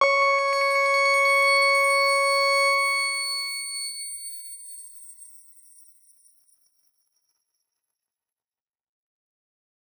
X_Grain-C#5-ff.wav